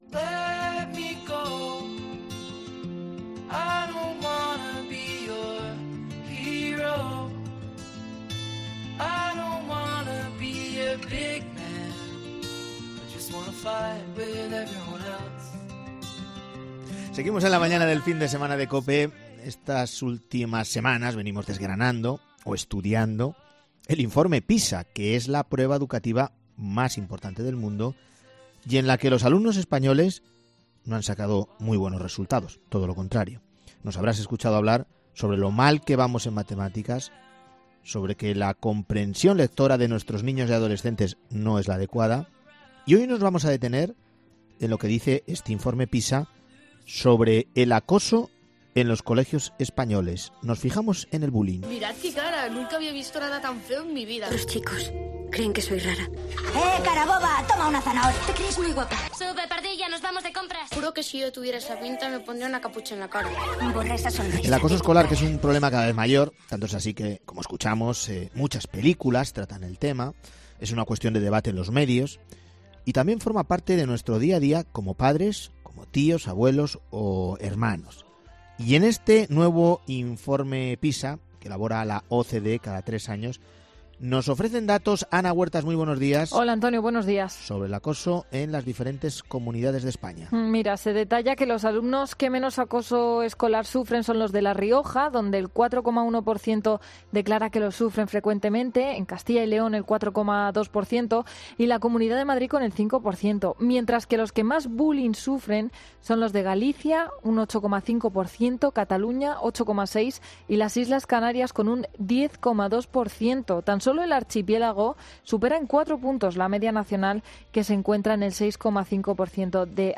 Mira, en COPE, hemos escuchado testimonios de diversos padres